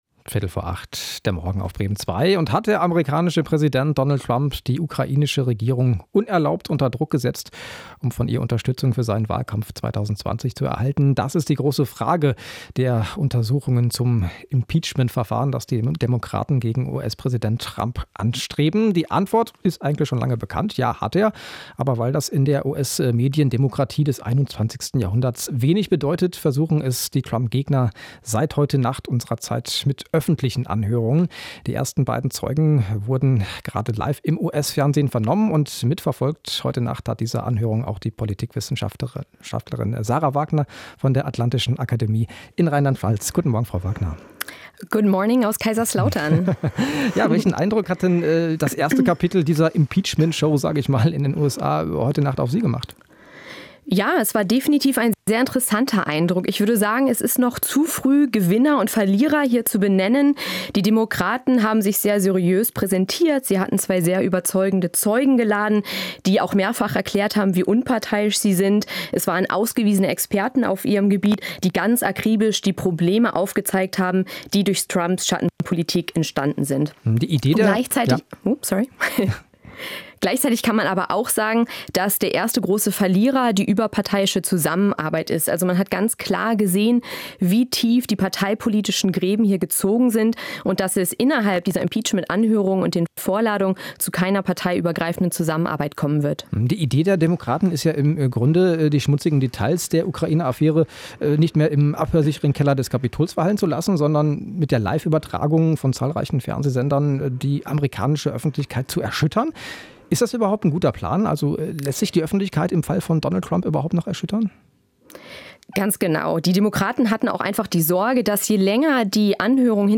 Interview zum Thema Impeachment